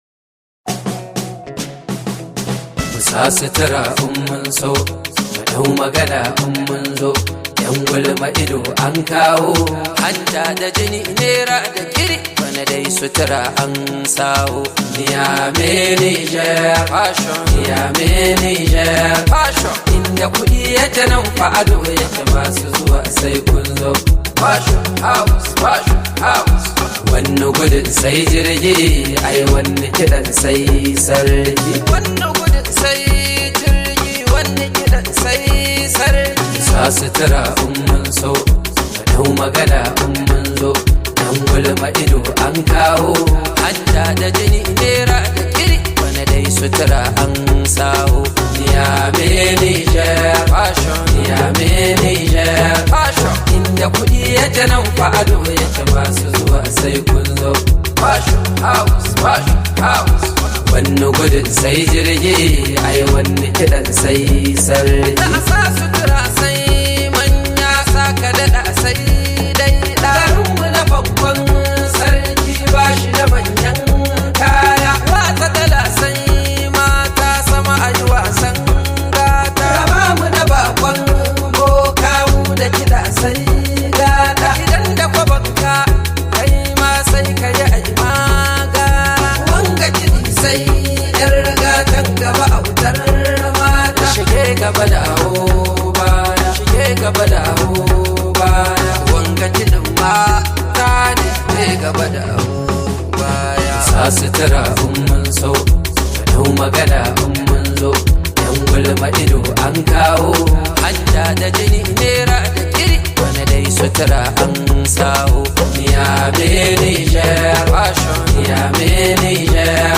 hausa song
high vibe hausa song